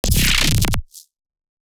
OTT Artifact 1.wav